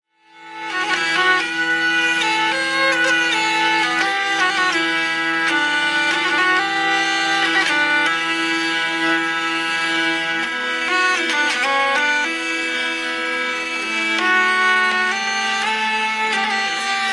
DIY Learn a Language - Ukrainian Musical Instruments
Lira
Lira.mp3